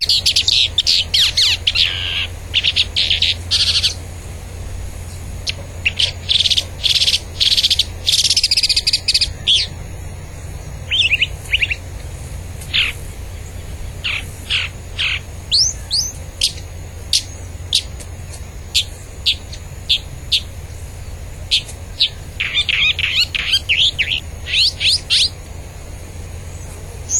Calhandra-de-três-rabos (Mimus triurus)
Nome em Inglês: White-banded Mockingbird
Fase da vida: Adulto
País: Uruguai
Localidade ou área protegida: Corralitos
Condição: Selvagem
Certeza: Fotografado, Gravado Vocal
Calandria-tres-Colas.mp3